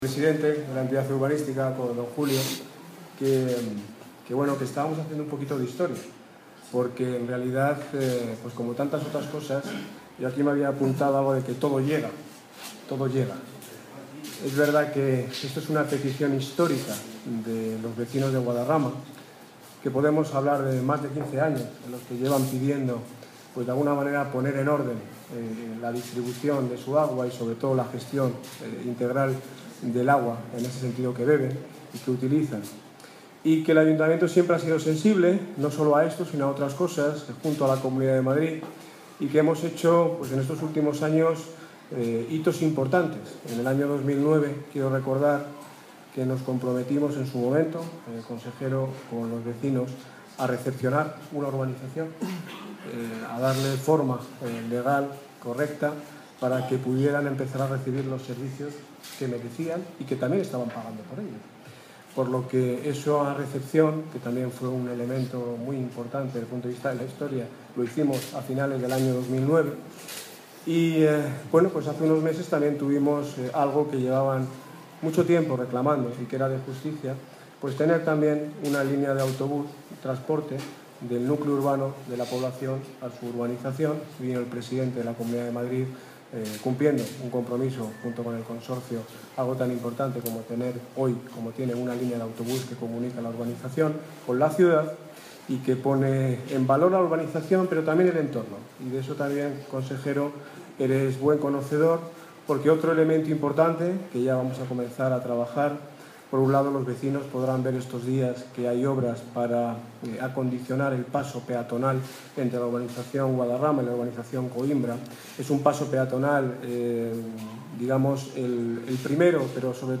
Audio - Daniel Ortiz (Alcalde de Móstoles ) Sobre Firma Convenio Canal Isabel II